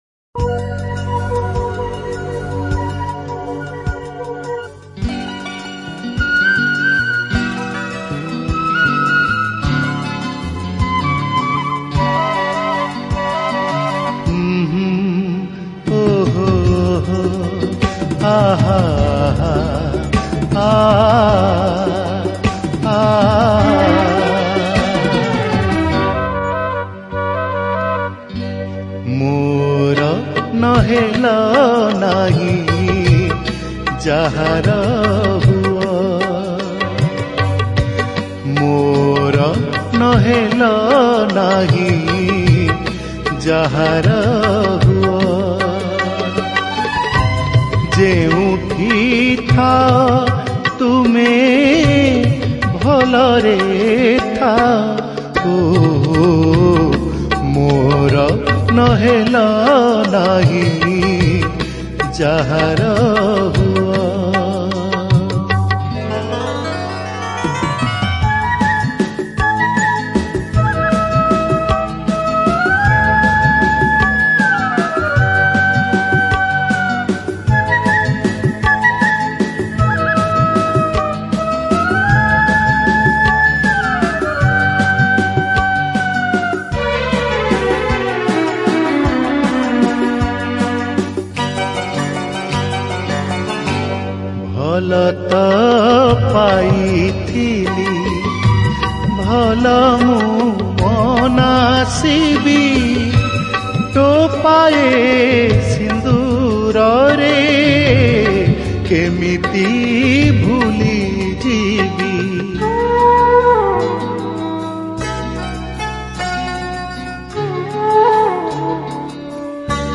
Bhajan Song